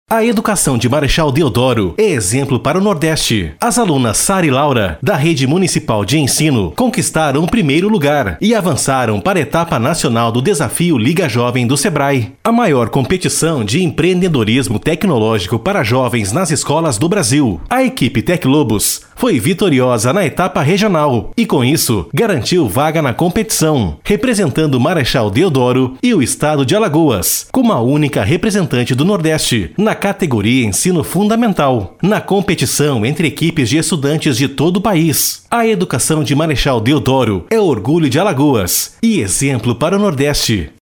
OFF - EDUCAÇÃO ALAGOAS: